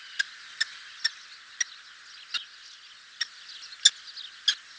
FALCO TINNUNCULUS - KESTREL - GHEPPIO
A female Kestrel came back to the nest, where she had the eggs. - POSITION: Poponaio farm near Grosseto town, LAT.N 42°47'/LONG.E 11°06'- ALTITUDE: 12 m. - VOCALIZATION TYPE: the female uttered these calls when she was sitting on the eggs.